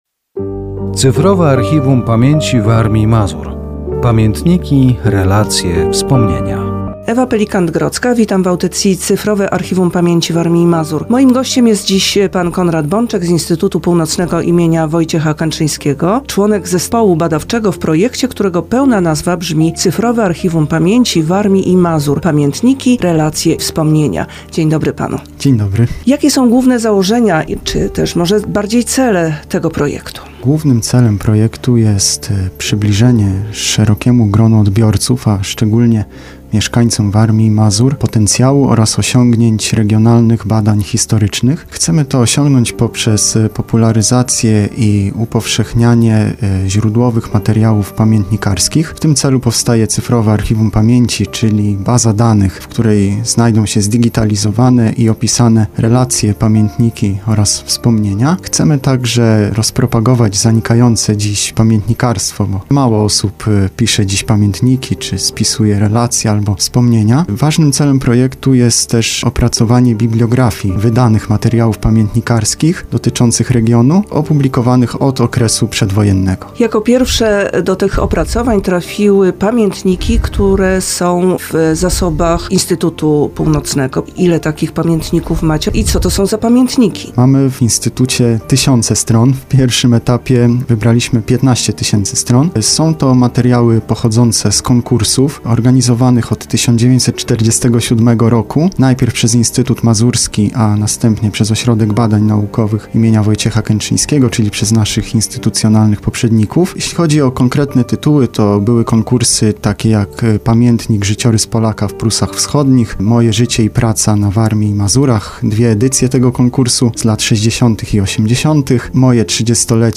Audycja radiowa poświęcona projektowi "Cyfrowe Archiwum Pamięci Warmii i Mazur. Pamiętniki, relacje, wspomnienia".